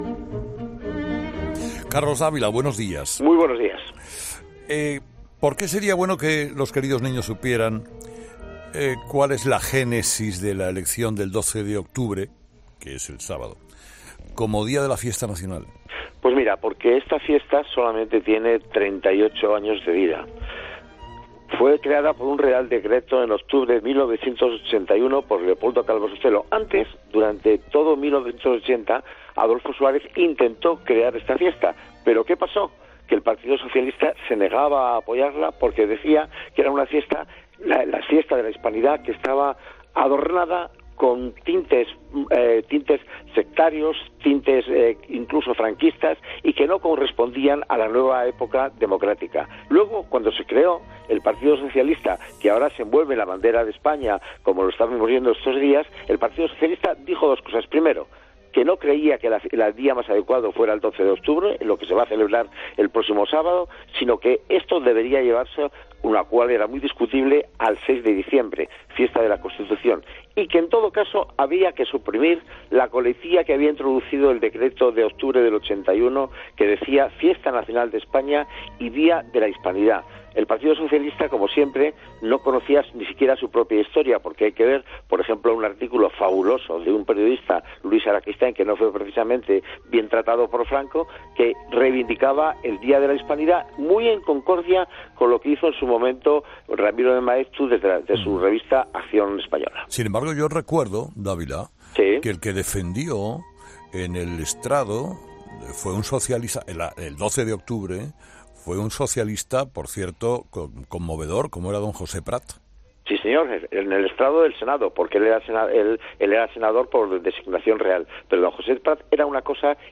Entrevista con Carlos Dávila
El periodista y escritor Carlos Dávila, ha contado en la sección “Queridos Niños”, de Herrera en COPE, el origen de la fiesta nacional que se celebra este sábado, 12 de octubre.